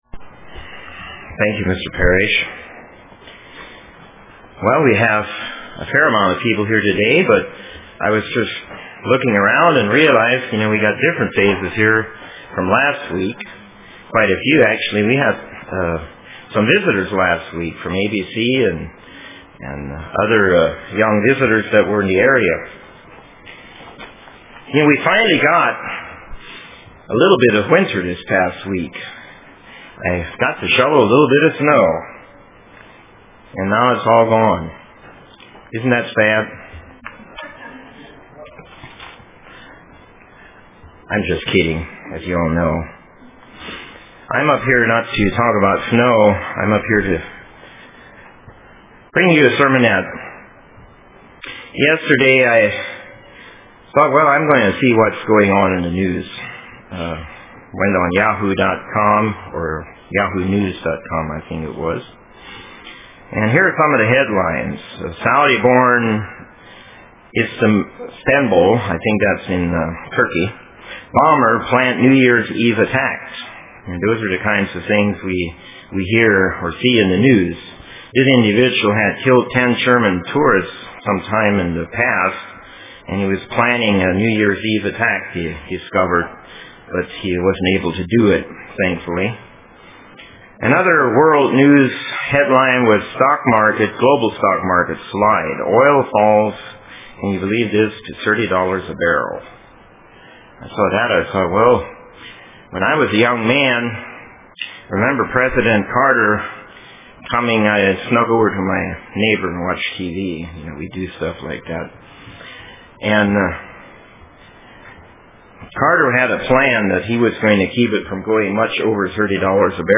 Print Watch UCG Sermon Studying the bible?